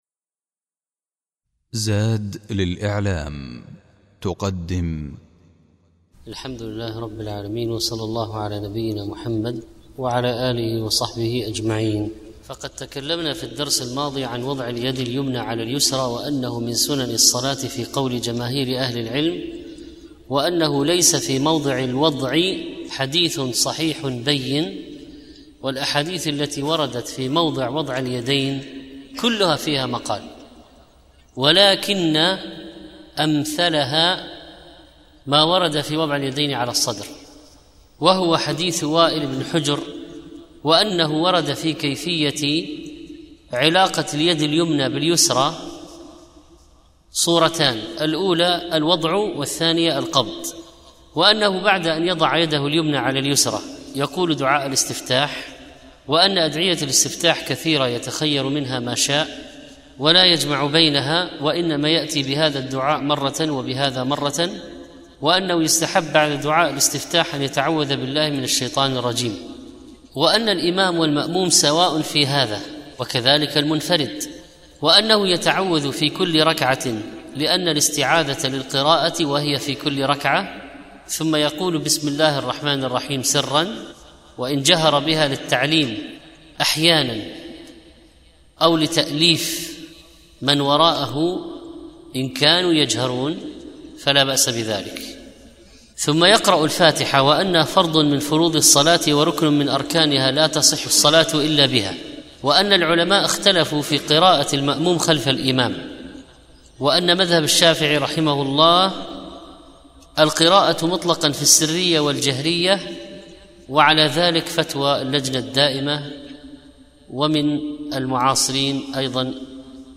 القراءة في الصلاة - الدرس الأول